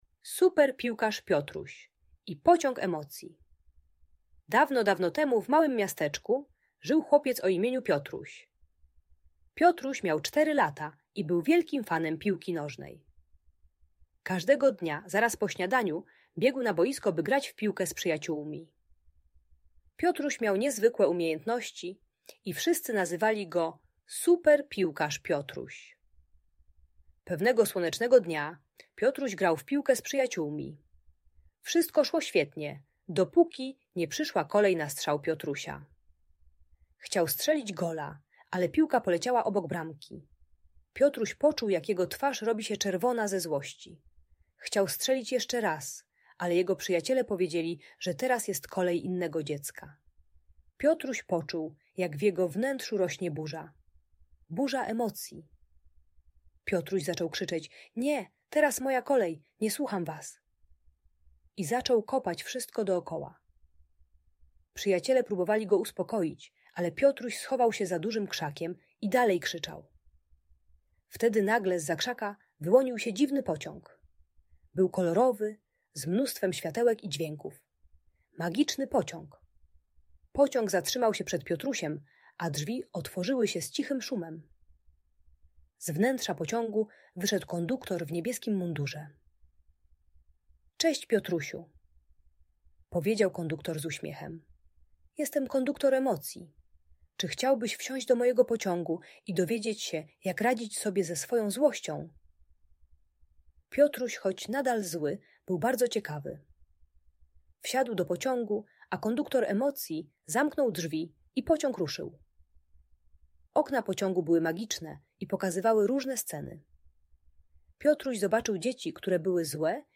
Super Piłkarz Piotruś i Pociąg Emocji - Audiobajka